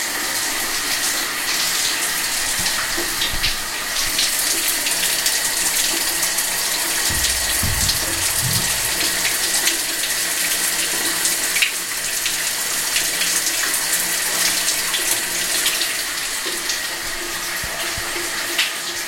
浴室用品 " 淋浴房AB
标签： 浴室 卫生间 跑步 淋浴
声道立体声